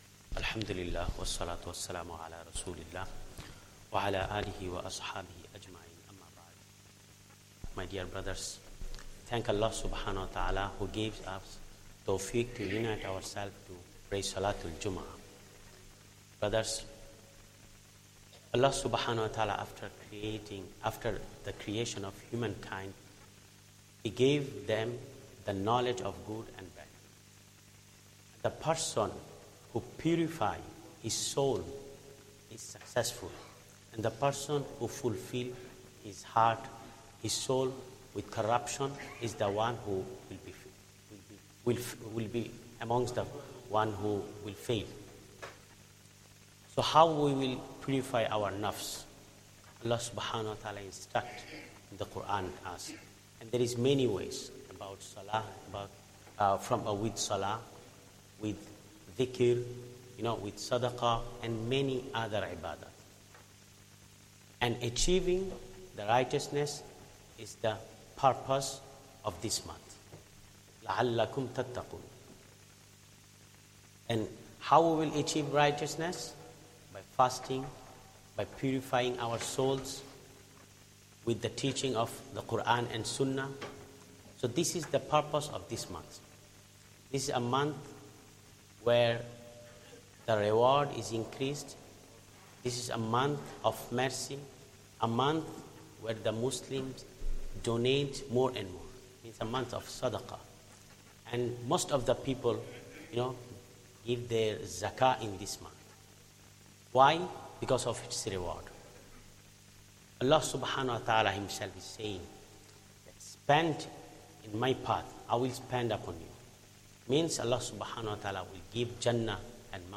Jummah Talk and 3rd Khutbah